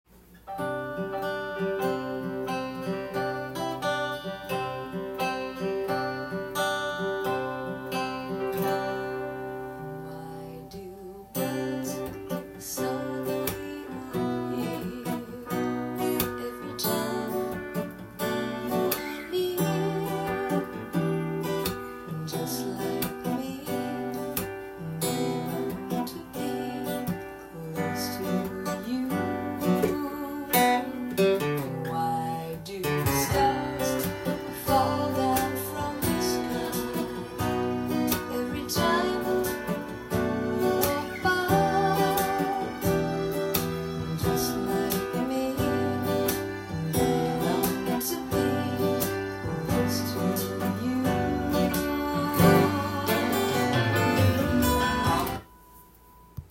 音源に合わせて譜面通り弾いてみました
リズムが８分音符のハネ 表記が記載してあります。
右手で弦を叩くとパーカッシブなミュート音が出せますので